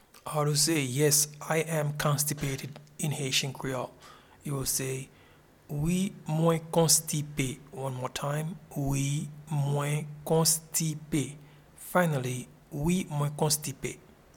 Pronunciation and Transcript:
Yes-I-am-constipated-in-Haitian-Creole-Wi-mwen-konstipe.mp3